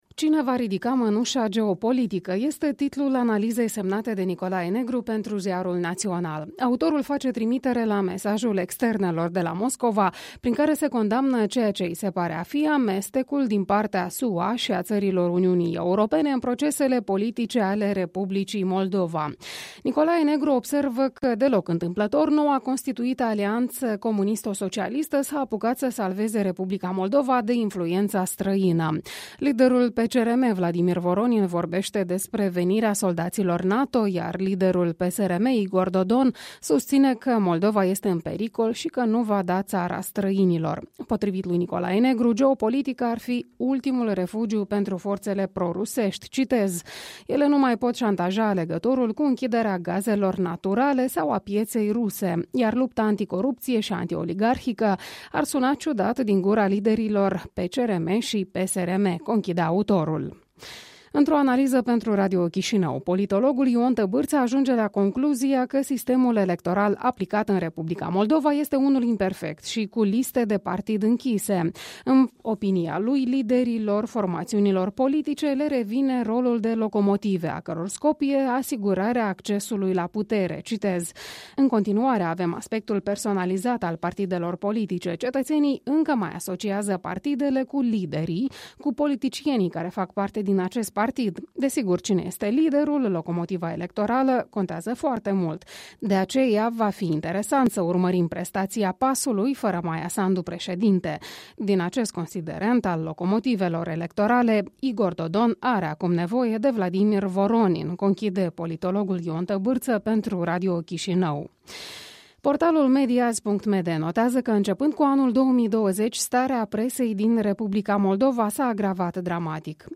Revista matinală a presei de la Chișinău.